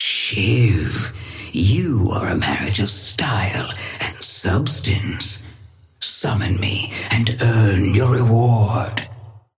Sapphire Flame voice line - Shiv, you are a marriage of style and substance.
Patron_female_ally_shiv_start_01.mp3